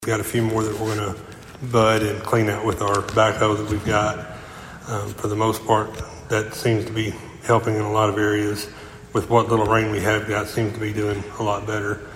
The Princeton City Council’s meeting Monday night was brief but packed with positive updates, as department heads shared news of new hires, ongoing projects, and community events.